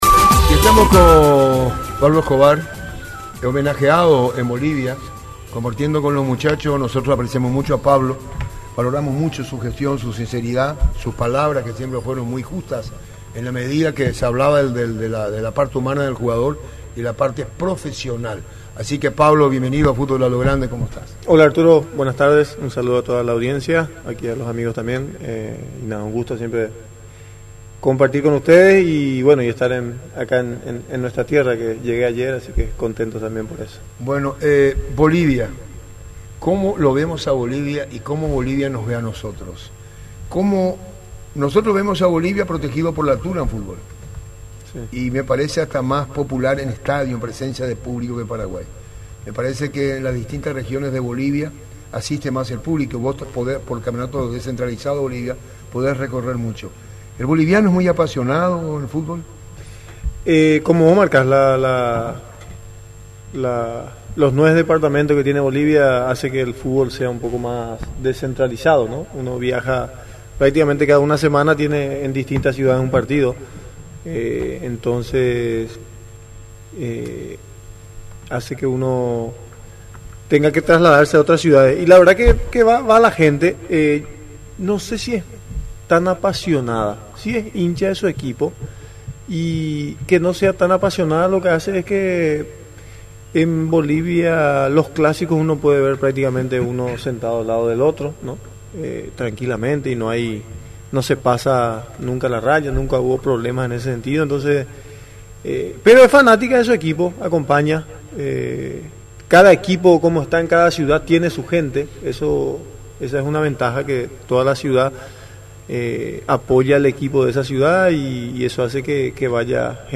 Invitado en estudios de Radio Monumental, el exfutbolista Pablo Escobar habló sobre sus proyectos deportivos.